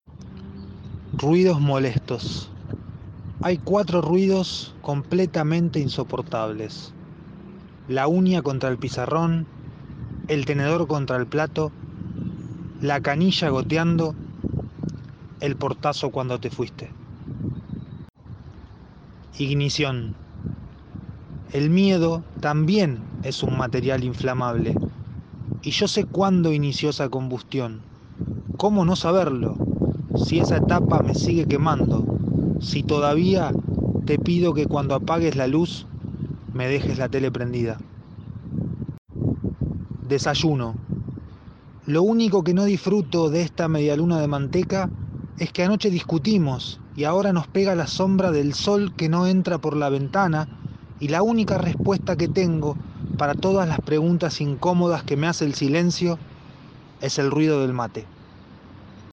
Tres poemas en la voz de su autor